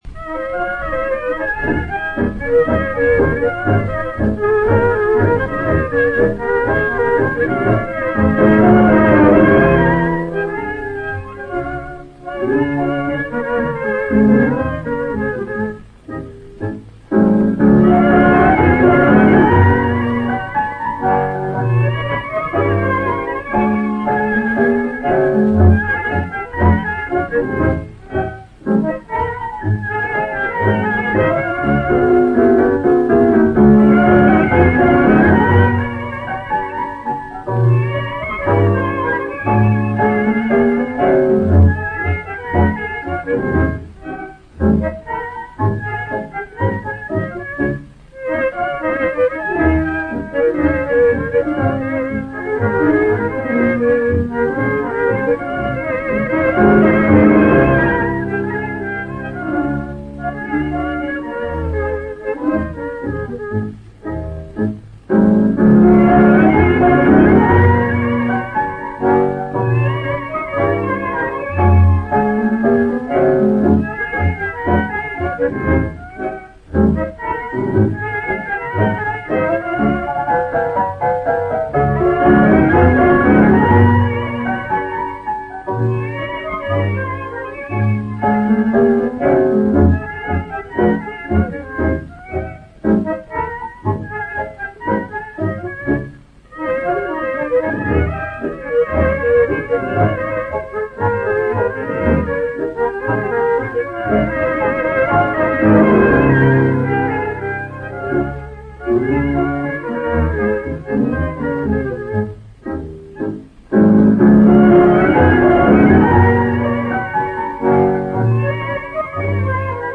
Pour les mêmes.